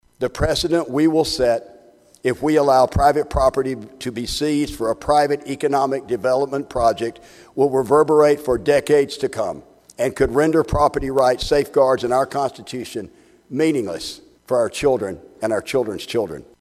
HOLT SAYS THE CARBON PIPELINE MAY BE BENEFICIAL TO SOME, BUT IT DOESN’T SERVE A PUBLIC PURPOSE.